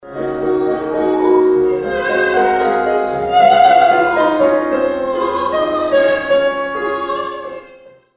U Vijestima prenosimo insert sa sinoćenjeg koncerta u Dvorcu